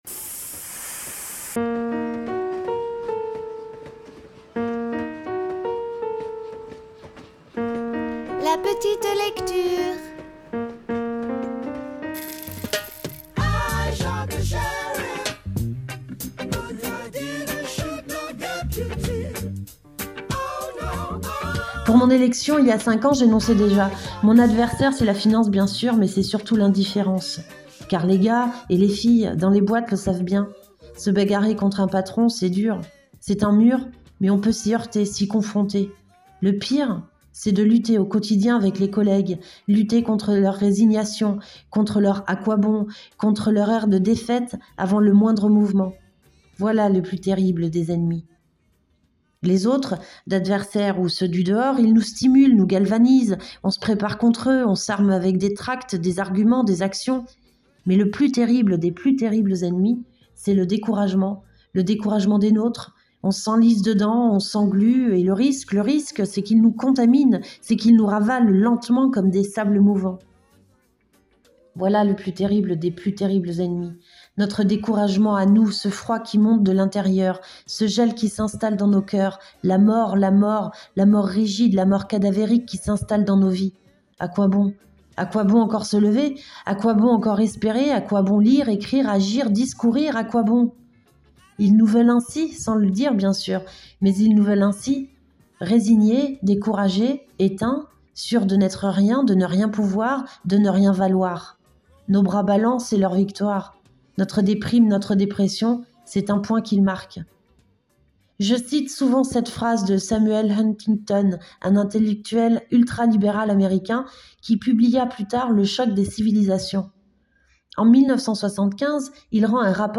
Mai 2022 – Discours d’un député de Gauche